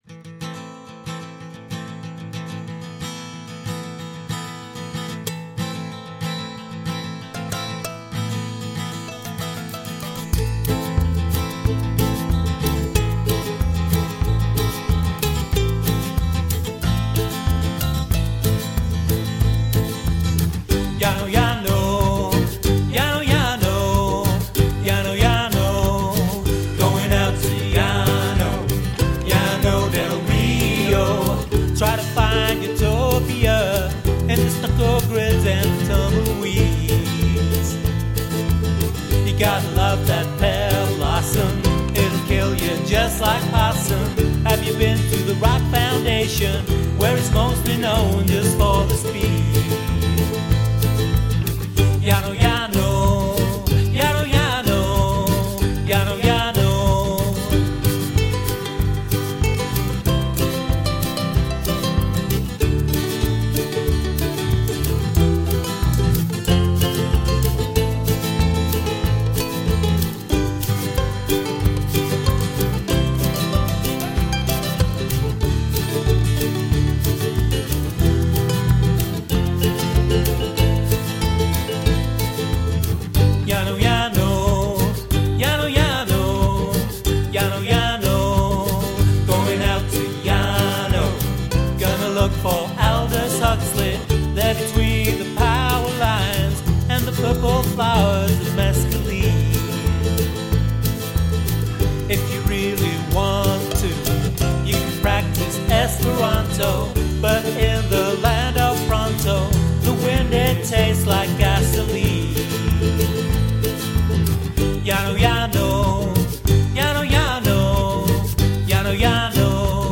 Acoustic guitar, electric bass, vocals, backing vocals
Ukulele, Cabasa x2, ride cymbal, floor tom, backing vocals.